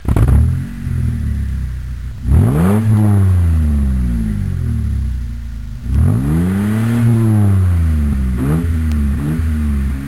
Dodge Stealth start and rev